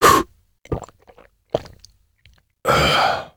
inv_vodka.ogg